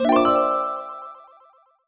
jingle_chime_14_positive.wav